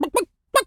chicken_cluck_bwak_seq_03.wav